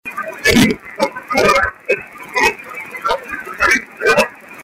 I did not talk at all during the recording session.